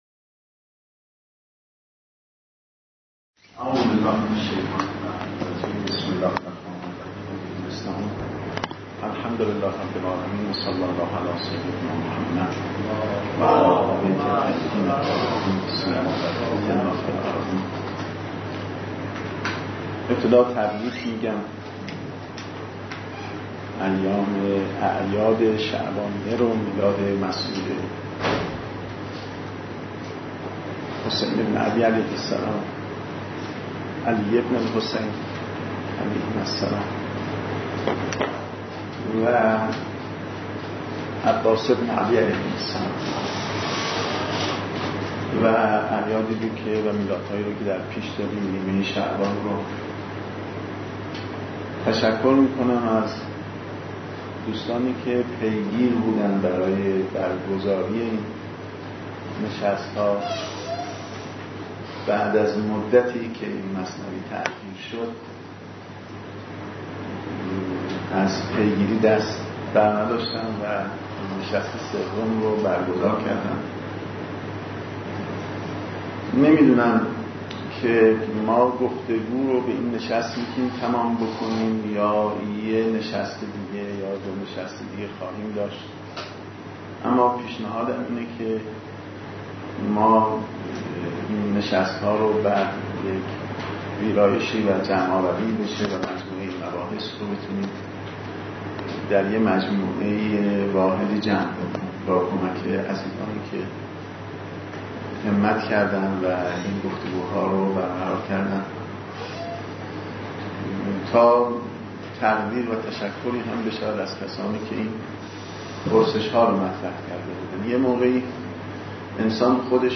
جلسه سوم نشست آسیب شناسی علوم اجتماعی اسلامی